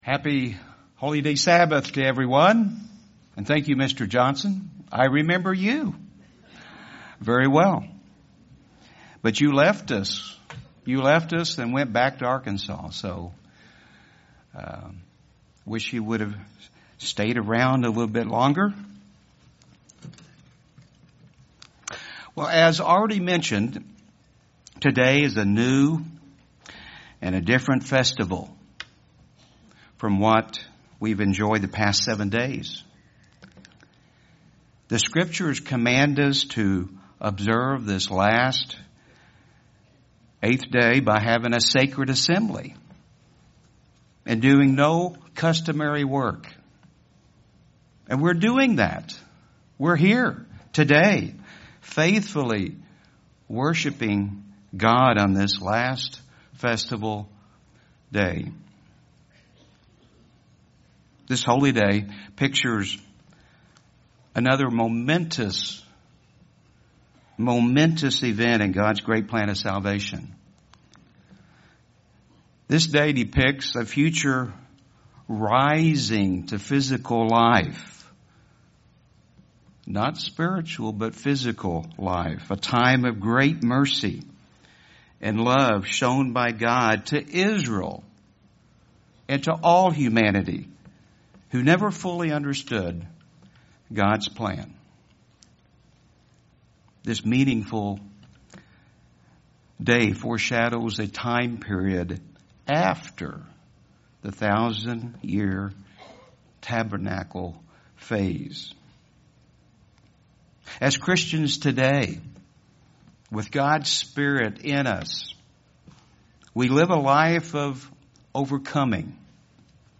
This sermon was given at the Branson, Missouri 2019 Feast site.